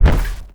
sci-fi_vehicle_thrusters_fail_01.wav